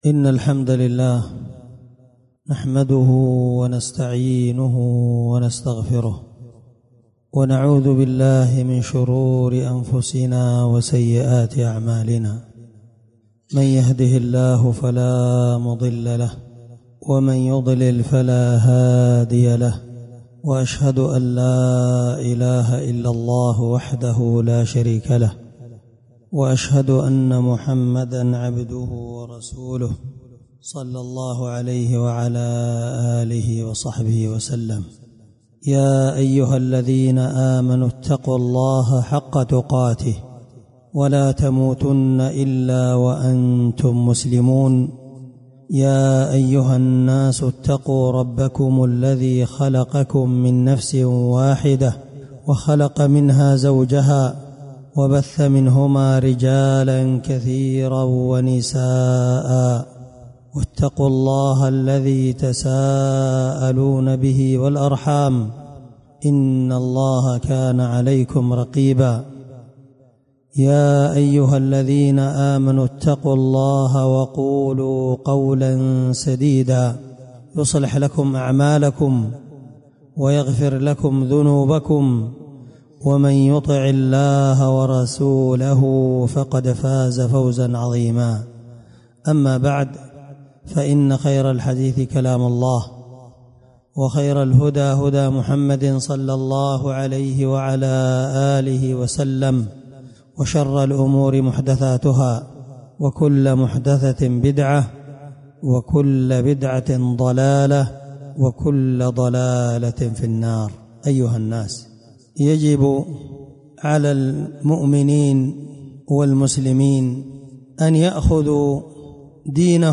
سلسلة خطب الجمعة